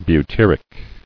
[bu·tyr·ic]